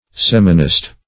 Search Result for " seminist" : The Collaborative International Dictionary of English v.0.48: Seminist \Sem"i*nist\, n. (Biol.)